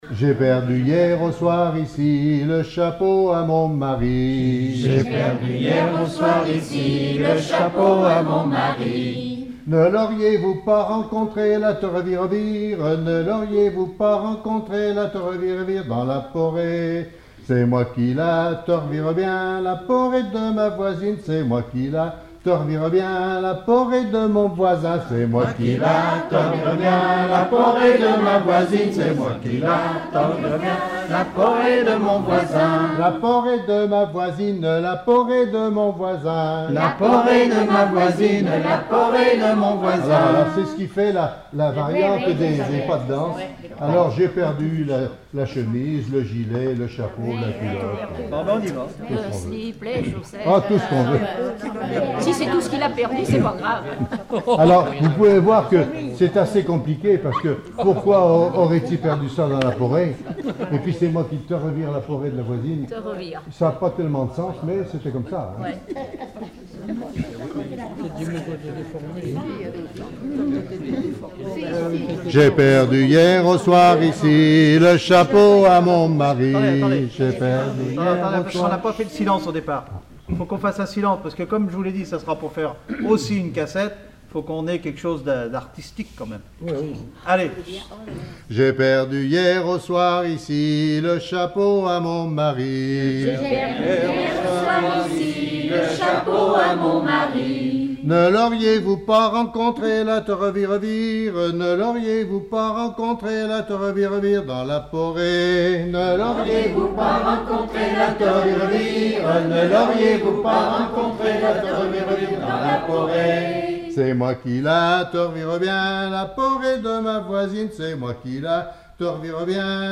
Patois local
danse : ronde : porée
Genre énumérative
Pièce musicale inédite